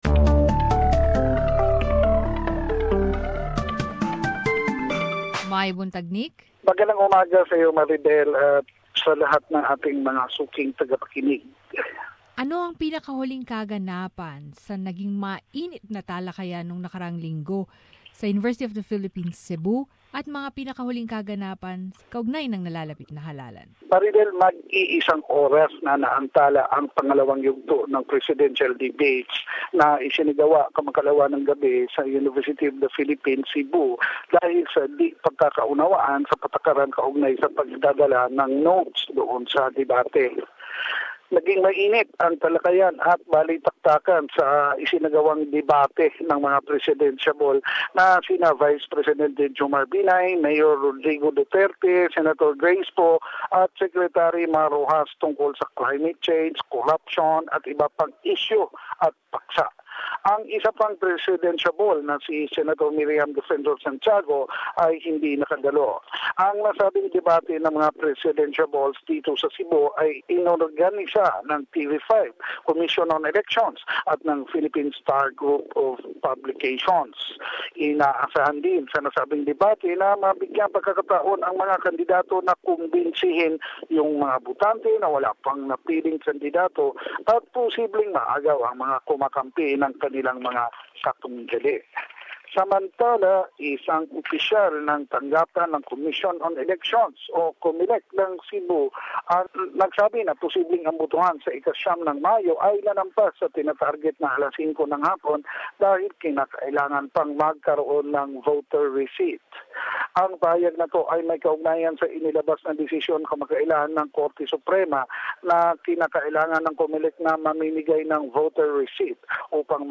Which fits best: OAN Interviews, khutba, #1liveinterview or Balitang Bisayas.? Balitang Bisayas.